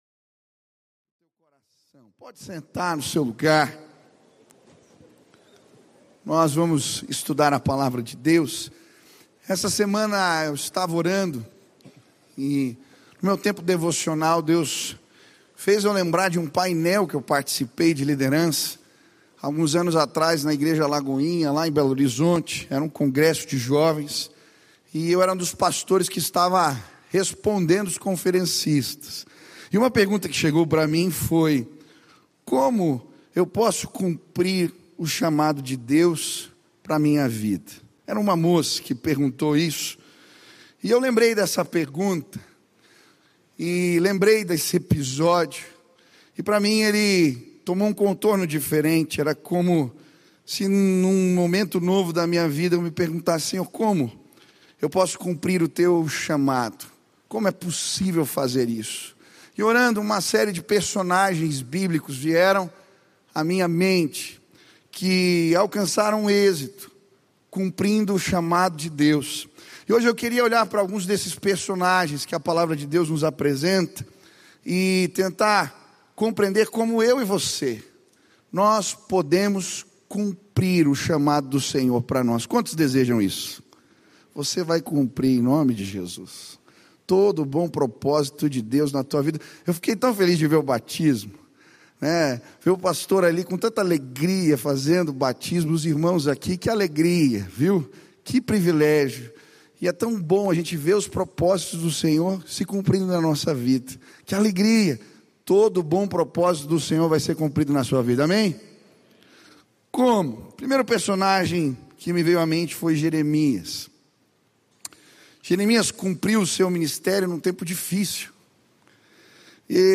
Mensagem
na Primeira Igreja Batista de Curitiba.